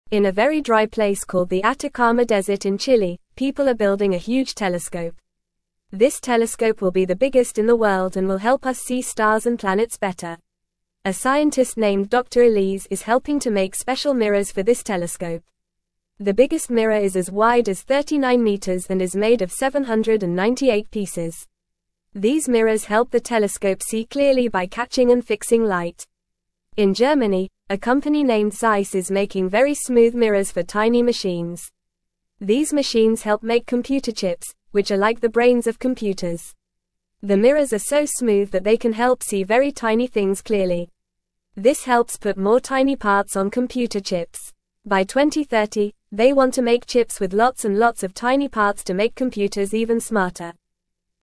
Fast
English-Newsroom-Beginner-FAST-Reading-Building-a-Big-Telescope-and-Making-Smooth-Mirrors.mp3